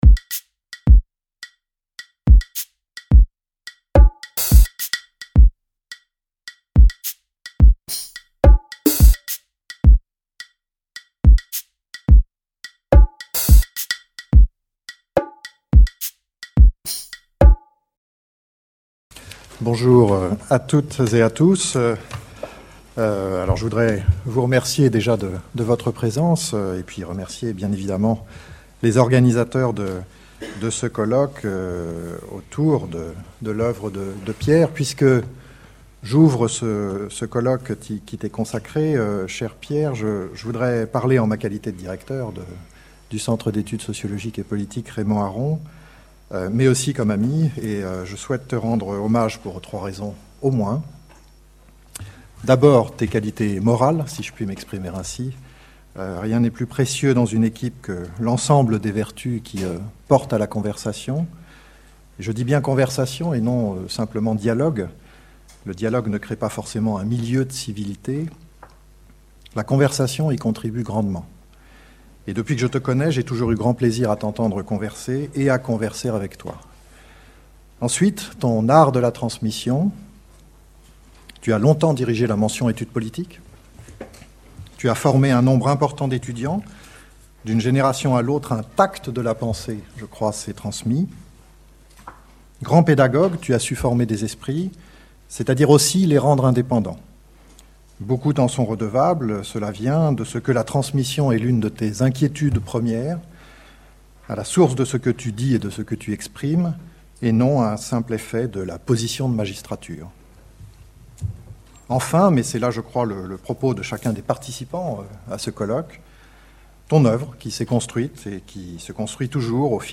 Quelle science du politique ? Colloque international autour de l’œuvre de Pierre Manent